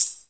normal-slidertick.ogg